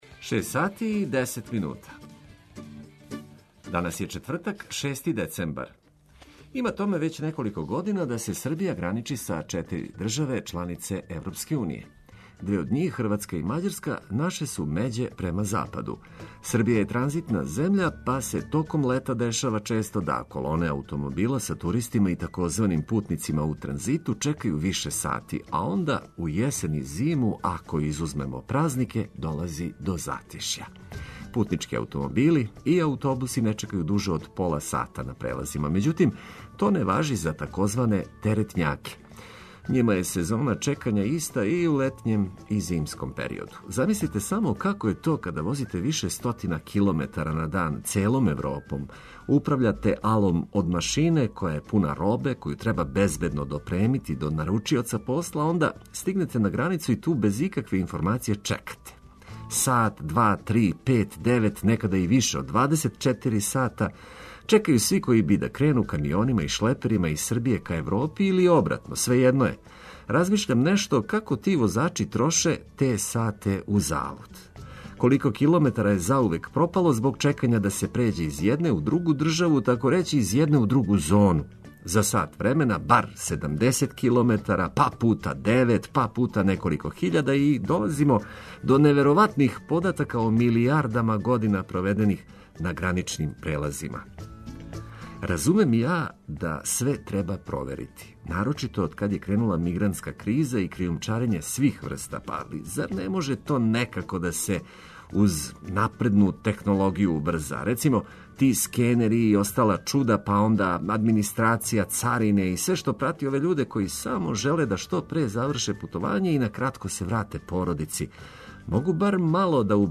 Ако је музика, биће најбоља, а ако су информације – биће само најважније.
Који су то услови, истражиће наш репортер на лицу места.